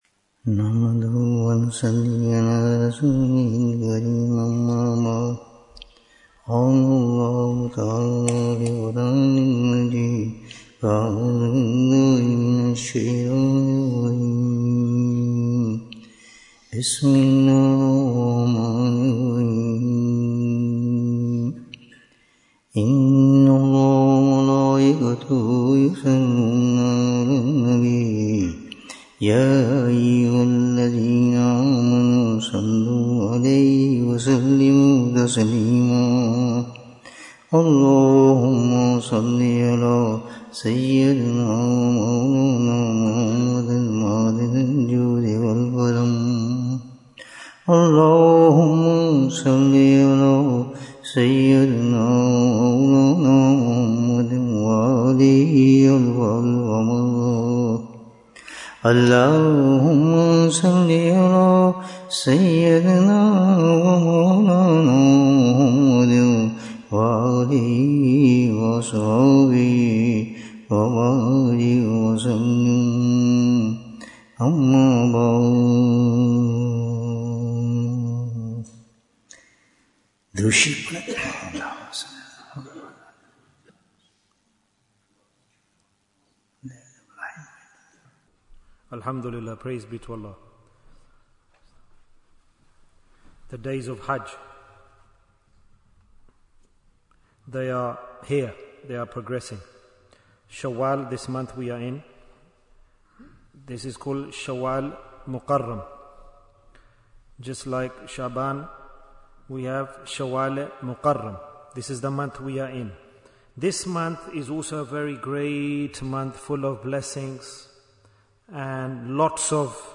The Blessings of Shawwal Bayan, 62 minutes10th April, 2025